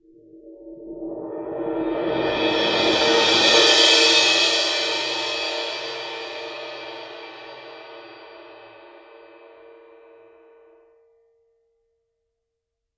susCymb1-cresc-Median_v1.wav